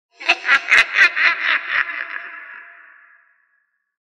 Witch Laughter Sound Effect
A chilling short horror laugh sound effect creates instant tension and fear.
Use it to enhance any Halloween project with a creepy, unsettling atmosphere.
Witch-laughter-sound-effect.mp3